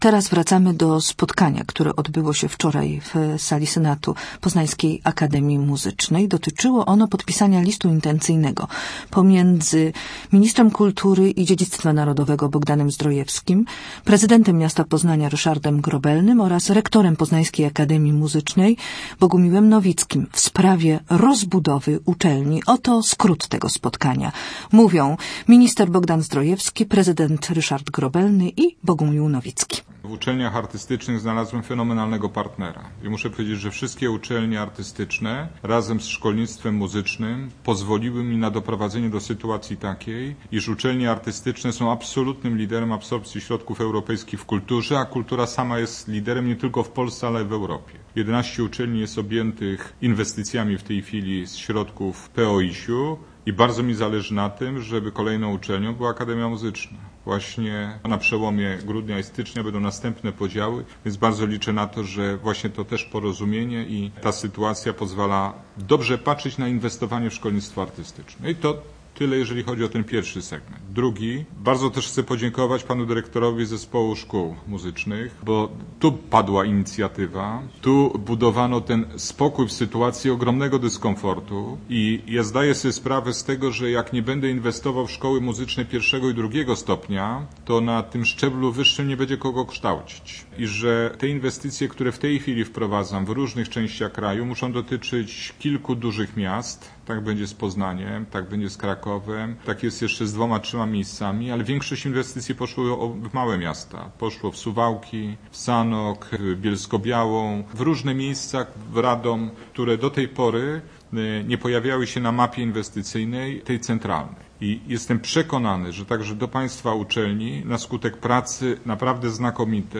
Na naszej stronie publikujemy zapis dźwiękowy uroczystego posiedzenia Senatu Akademii Muzycznej, na którym podpisano list intencyjny w sprawie rozbudowy uczelni i zarazem siedziby dla Zespołu Szkół Muzycznych z ulicy Głogowskiej.